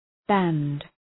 {bænd}